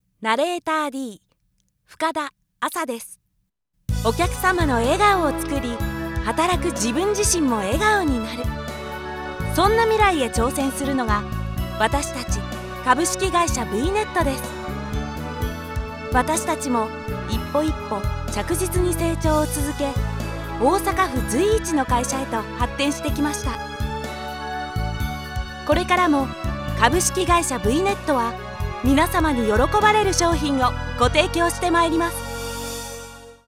ナレータサンプル
曲入り